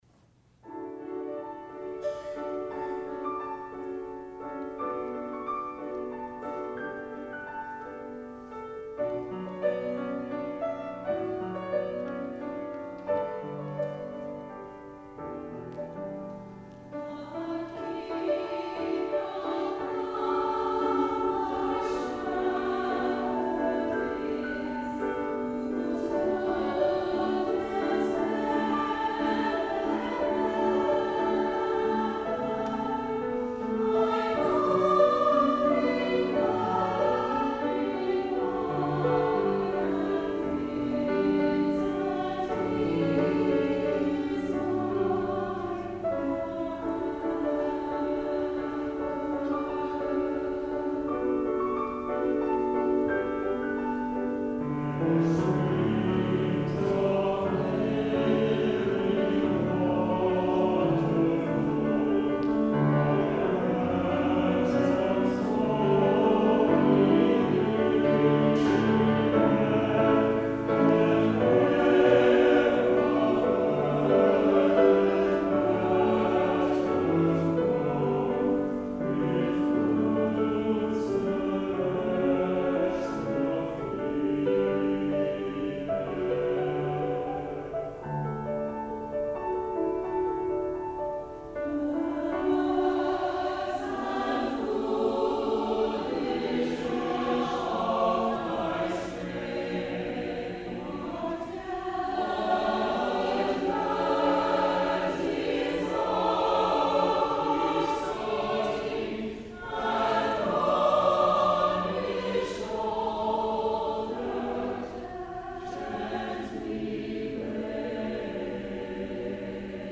Chancel Choir
piano